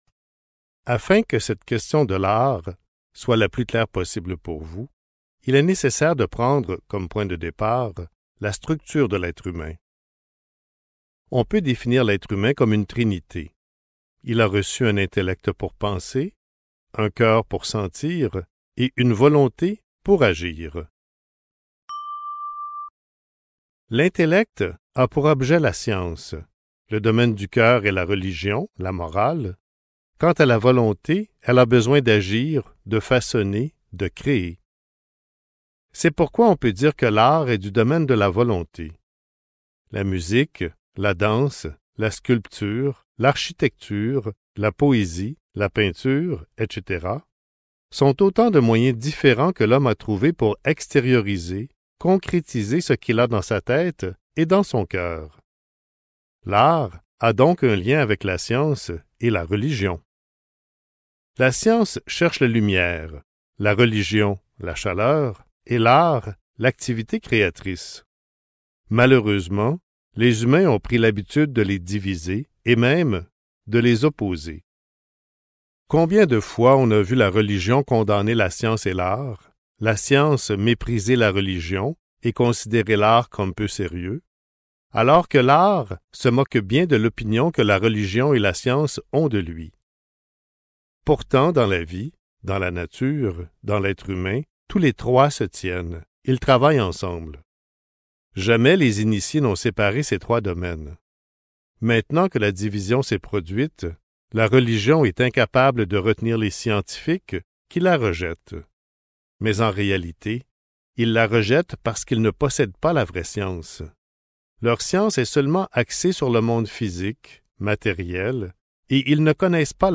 Création artistique et création spirituelle (Livre audio | CD MP3) | Omraam Mikhaël Aïvanhov